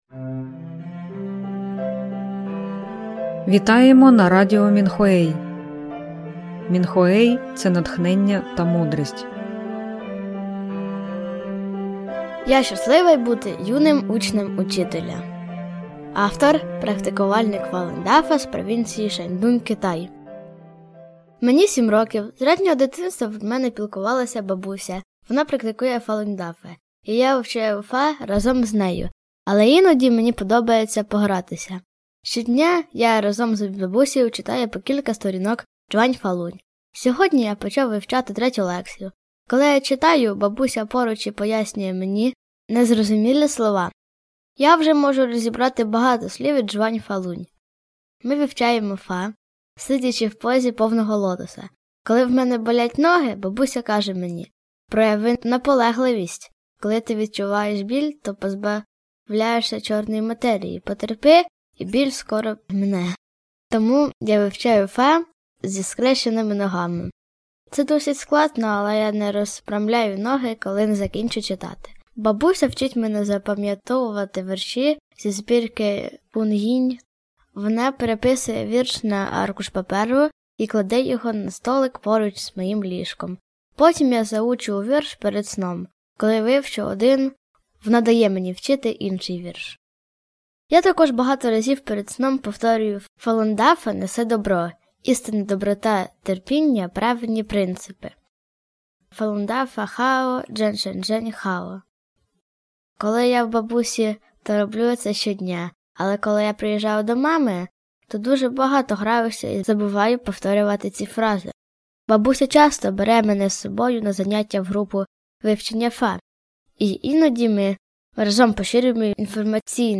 Музика з подкастів написана та виконана учнями Фалунь Дафа.